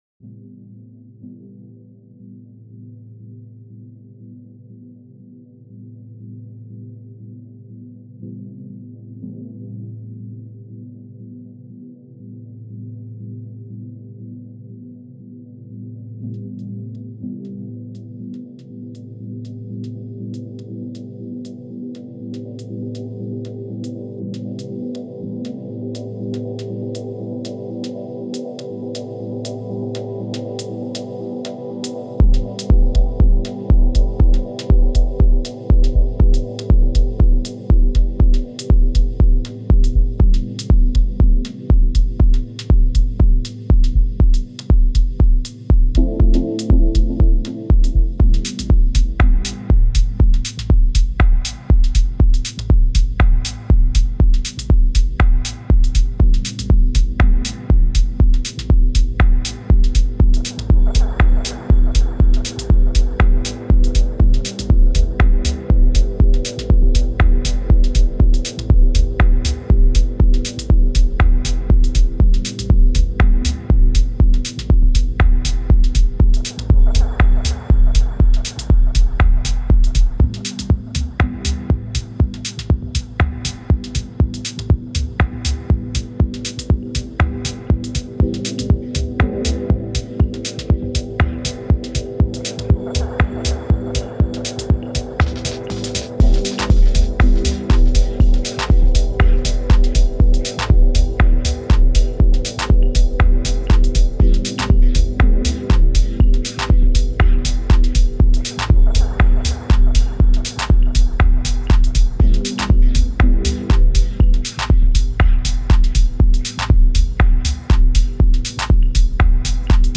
Genre: Dub Techno.